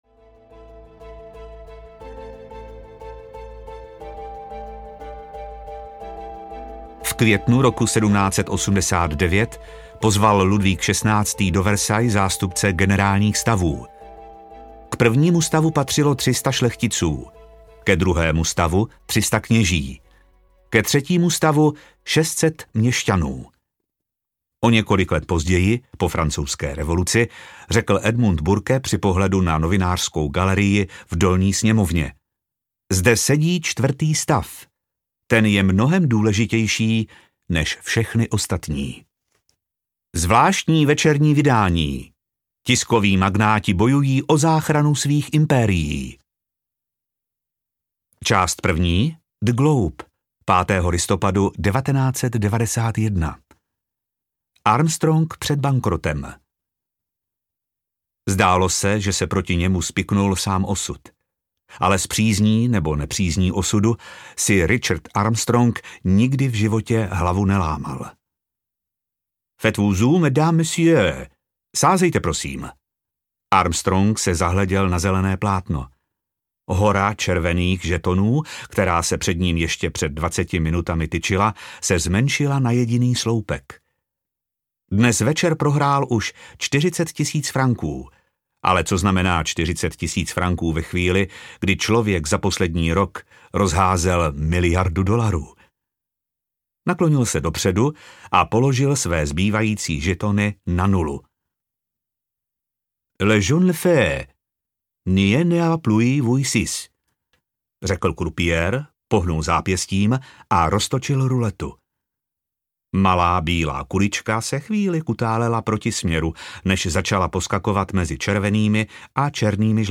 Čtvrtý stav audiokniha
Ukázka z knihy